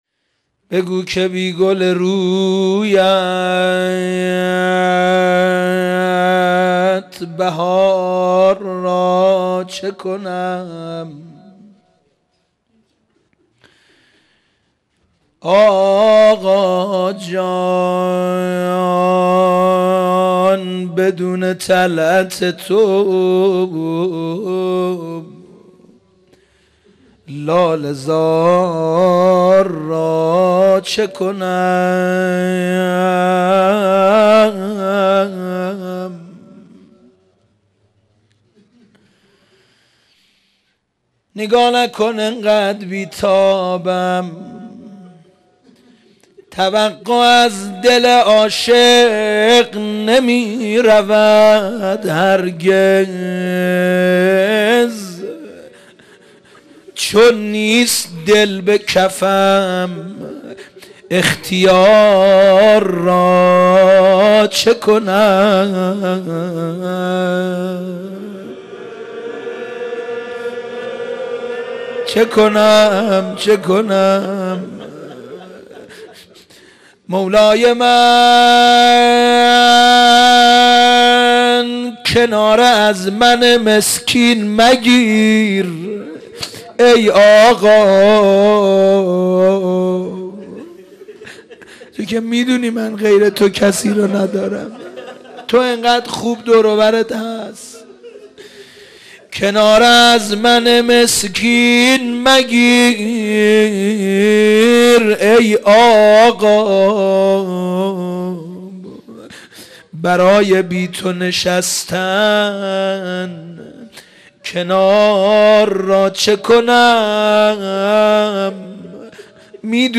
مناسبت : وفات حضرت زینب سلام‌الله‌علیها
قالب : روضه